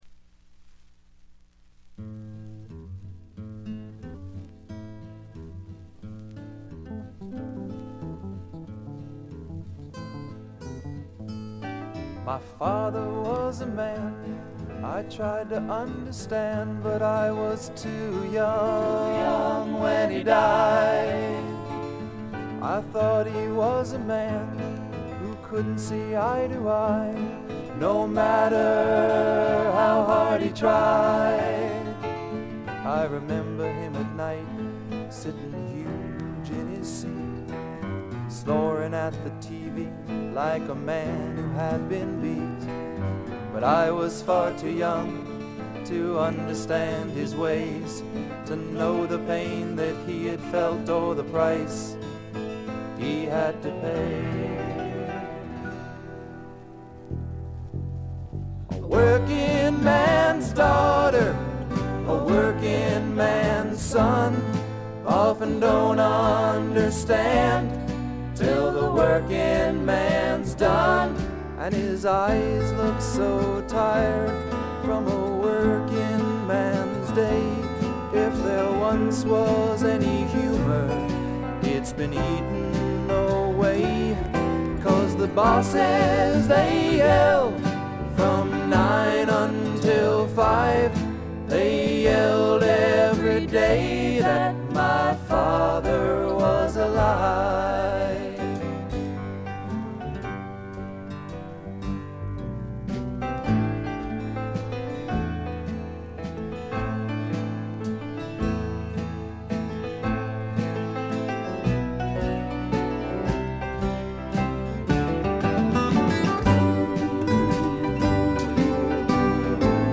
軽微なチリプチ少々。
しかし内容はいたってまともなフォーキー・シンガー・ソングライター作品です。
いかにも東部らしい静謐な空気感がただようなかに、愛すべきいとおしい曲が散りばめられた好盤です。
試聴曲は現品からの取り込み音源です。
Acoustic Guitar
Harmony Vocals [Back-up]